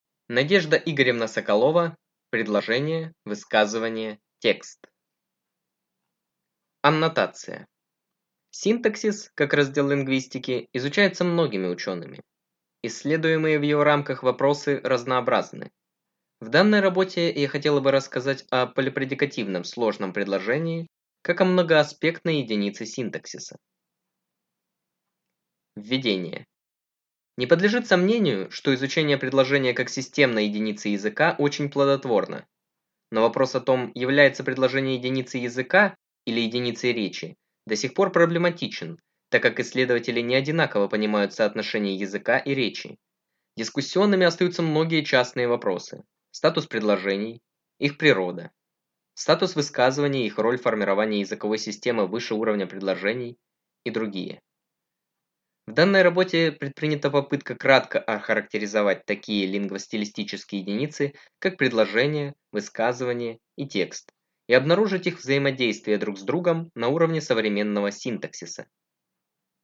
Аудиокнига Предложение – высказывание – текст | Библиотека аудиокниг